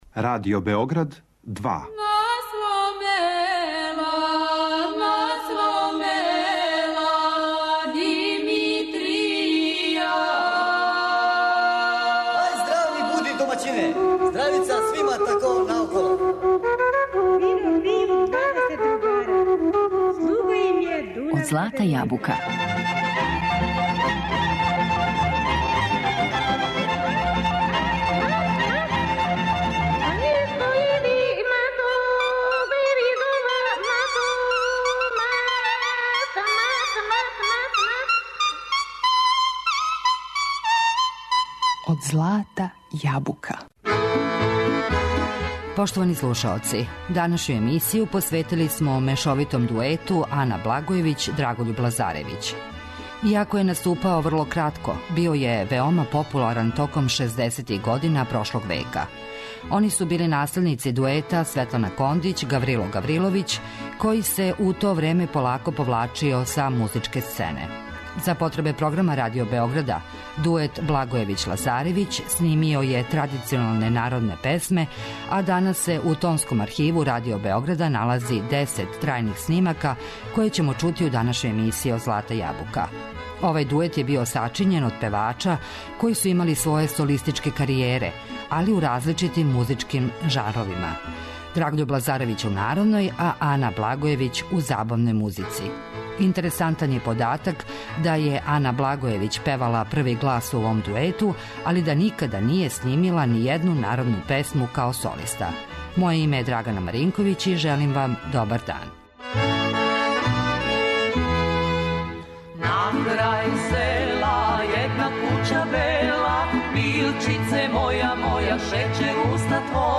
мешовитом дуету
За потребе Радио Београда снимали су традиционалне народне песме, а у тонском архиву налази се десет трајних снимака, које ћемо чути у данашњој емисији.